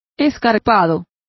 Complete with pronunciation of the translation of sheer.